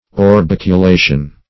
Search Result for " orbiculation" : The Collaborative International Dictionary of English v.0.48: Orbiculation \Or*bic`u*la"tion\, n. The state or quality of being orbiculate; orbicularness.